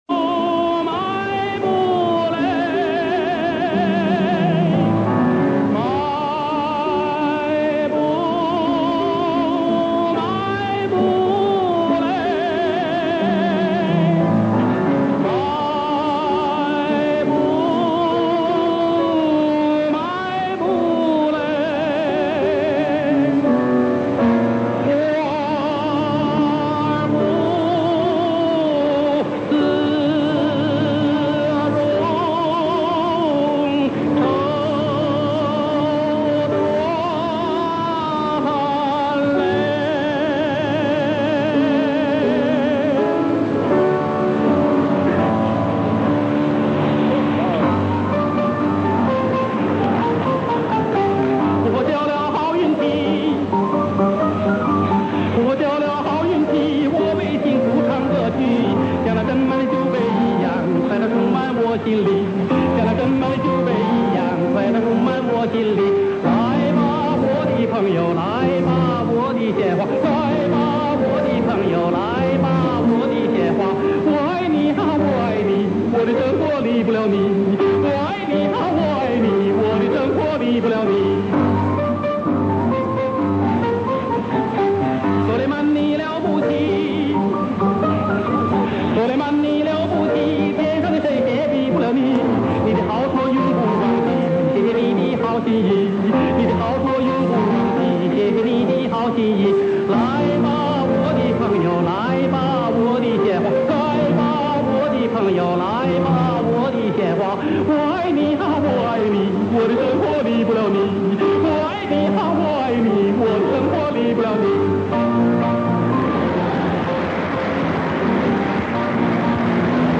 品味老歌,感动生活
这是本人录自电台的一组节目，由于录制于中波段，有些电磁干扰，见谅！